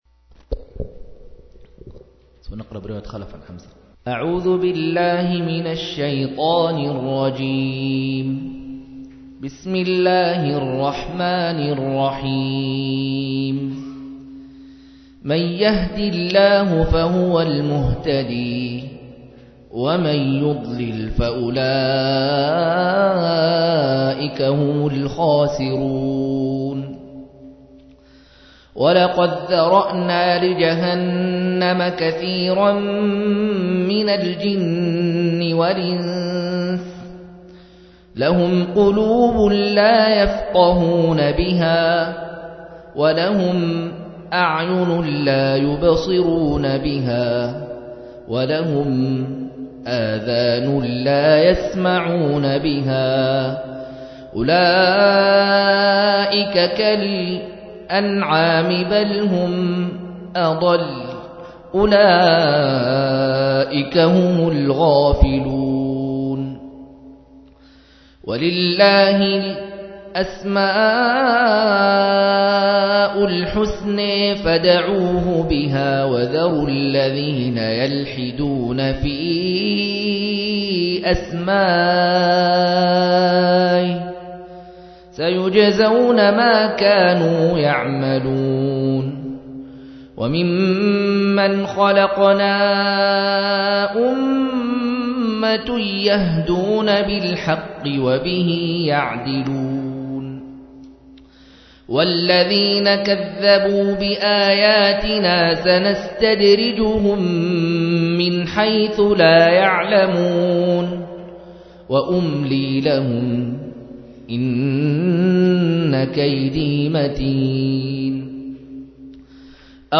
160- عمدة التفسير عن الحافظ ابن كثير رحمه الله للعلامة أحمد شاكر رحمه الله – قراءة وتعليق –